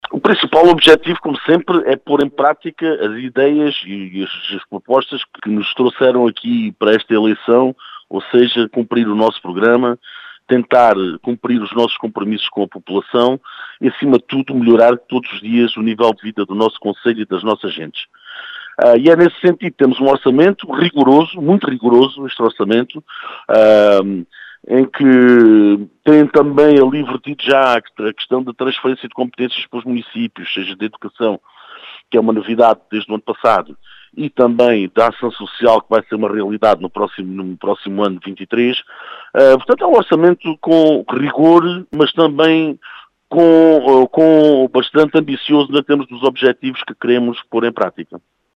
Em declarações à Rádio Vidigueira, Calos Teles, o presidente da Câmara Municipal de Aljustrel, fala num orçamento “muito rigoroso e ambicioso”.